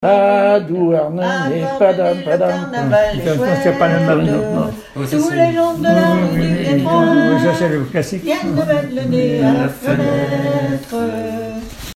Base d'archives ethnographiques
Catégorie Pièce musicale inédite